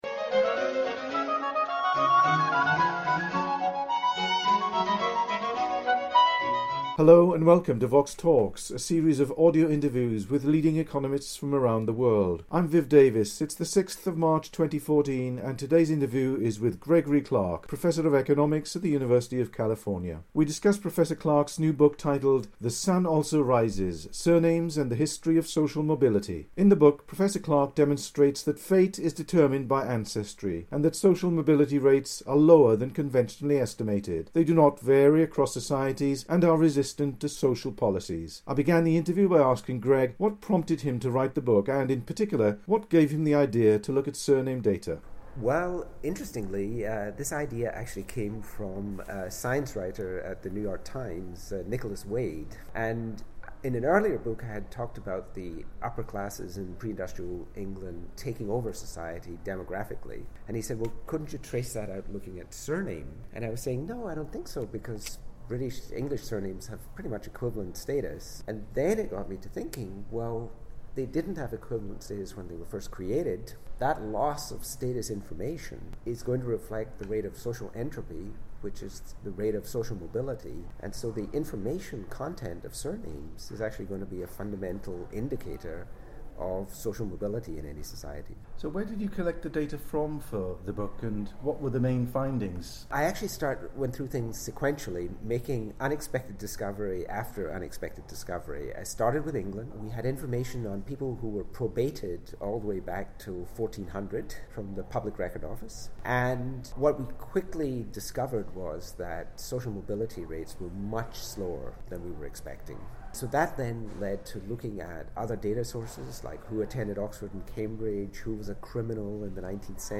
The interview was recorded in London in March 2014.